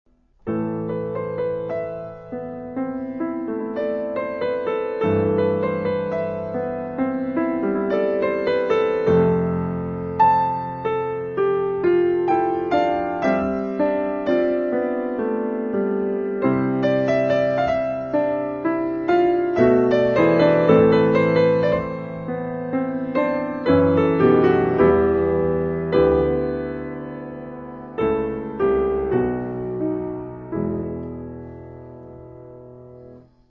Каталог -> Класична -> Фортепіано